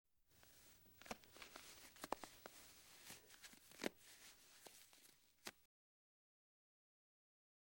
Cloth Put On Silk Stocking with Light Elastic Snap Sound
household